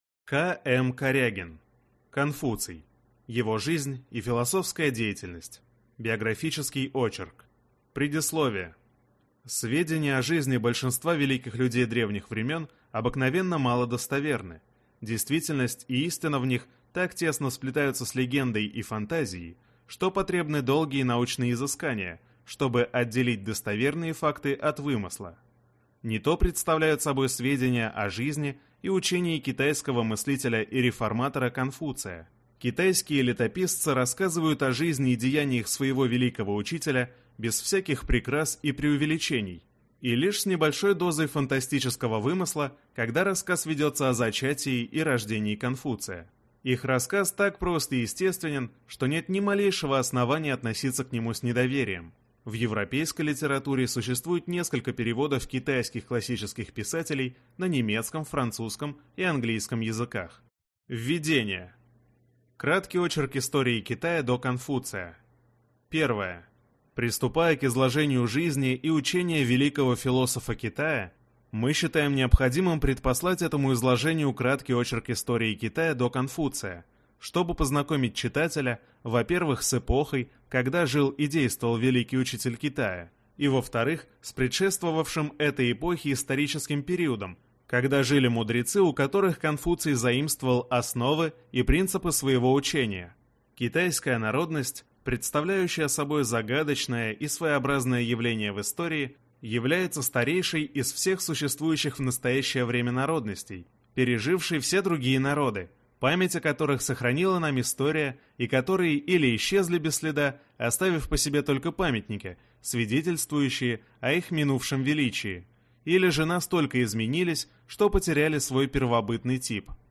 Аудиокнига Конфуций. Его жизнь и философская деятельность | Библиотека аудиокниг